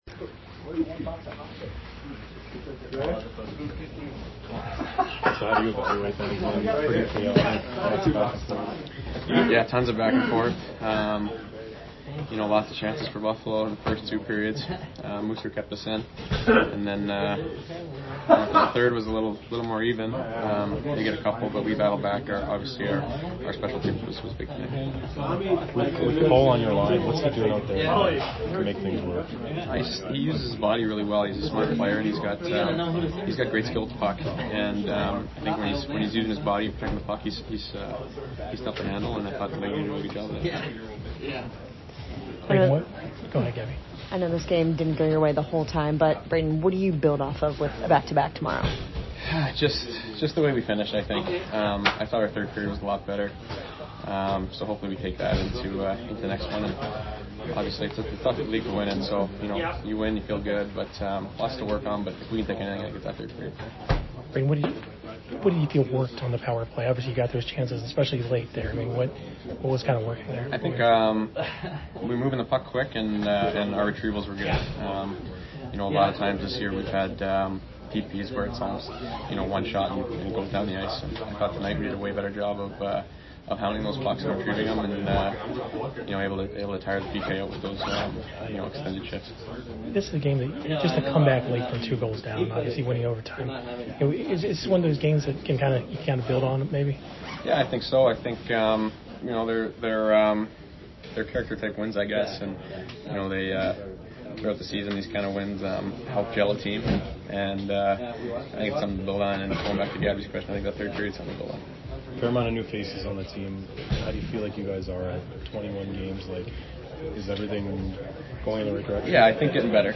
Brayden Point Post Game 11/28/22 at BUF